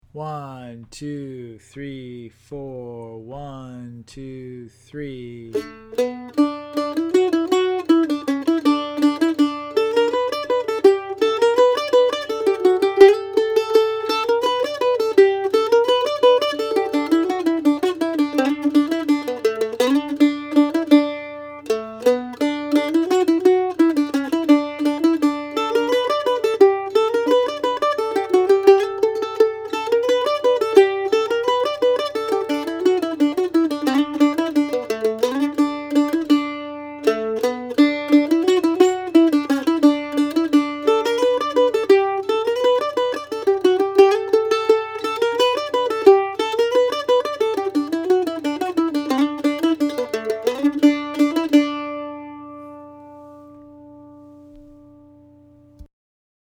The tune is played in the key of D with the usual three chords: D, G, and A. Download a pdf of Policeman -> Click Policeman.pdf .